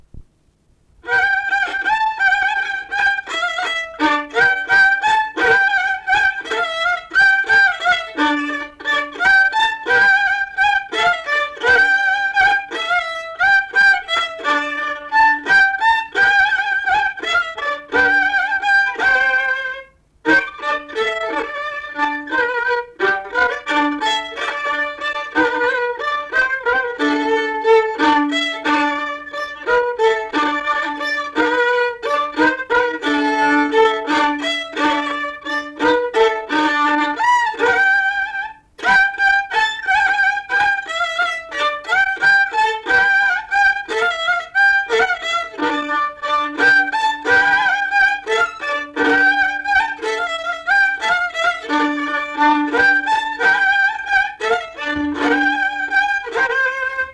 šokis
instrumentinis
smuikas